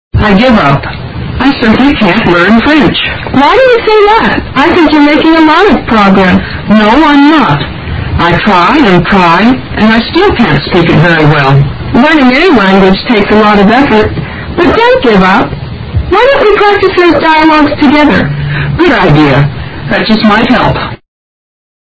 英语对话听力mp3下载Listen 22:LEARNING A LANGUAGE